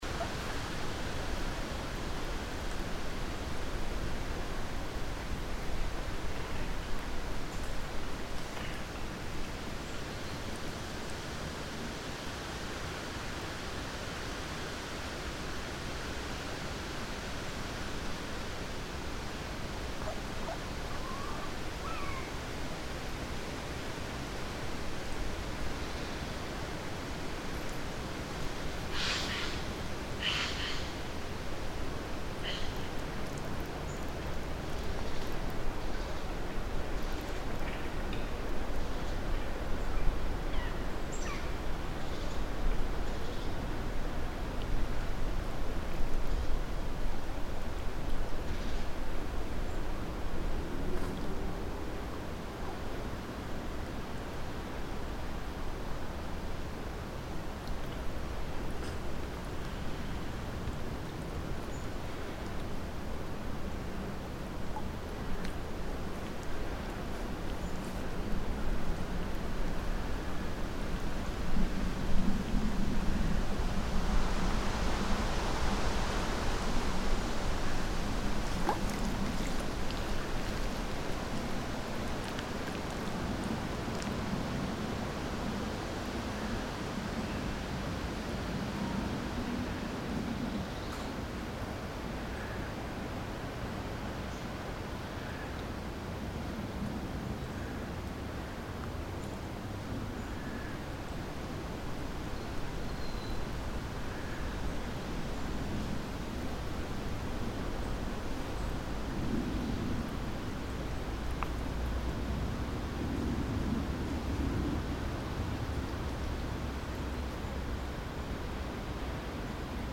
the eight recordings that constitute "soundmap vienna" originate partly from locations inside the city that bear an everyday relationship with me and partly have been "discovered" during a few occasional strolls. what they have in common is that they don’t bear an apparent reference to vienna, neither acoustically nor visually – no fiakers and no vienna boys choir, sorry for that – and that most of them were taken at unusual times during the day. these recordings were left unprocessed and subsequently arranged into a 19-minute long collage. the second, more experimental collage was composed out of midi-data and soundfiles that have been extracted via specific software from the photographs taken at the recording sites.